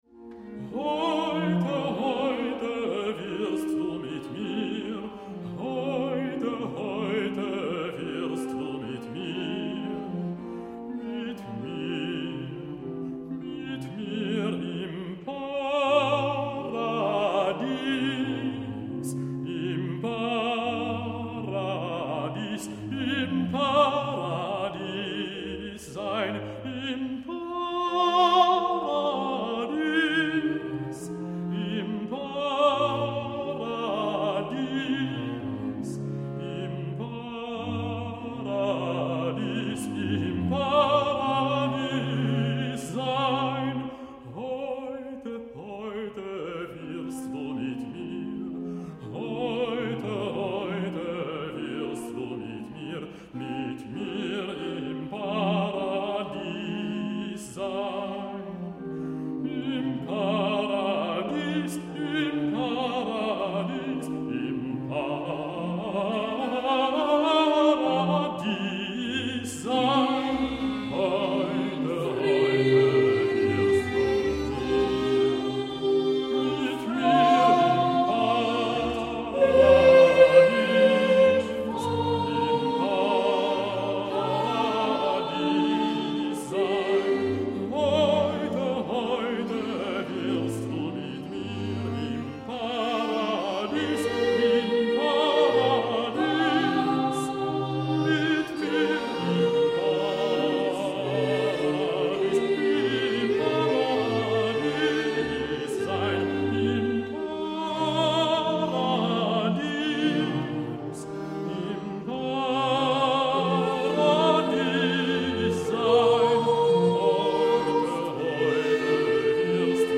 Ο τραγουδιστής λέει «σήμερον μετ’ εμού έση εν τω παραδείσω» και ταυτόχρονα ένα έγχορδο ακολουθεί τη δική του πορεία.